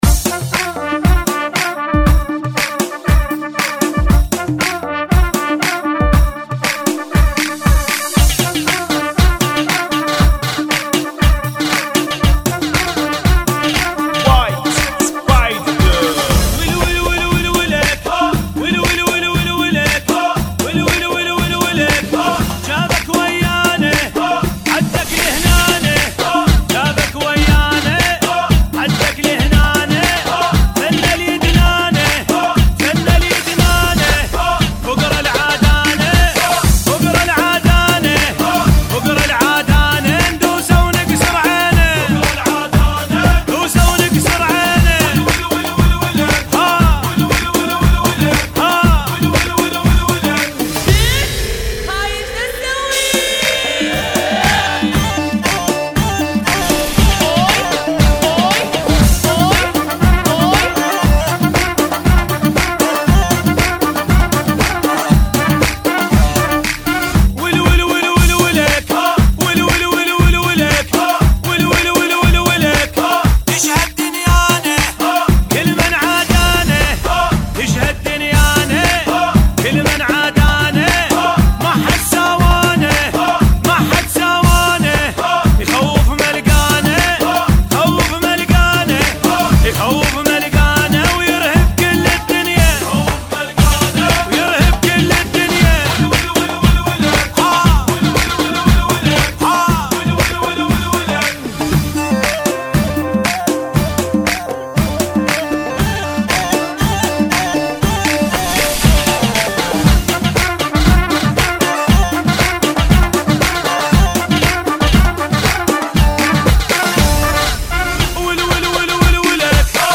Funky [ 118 Bpm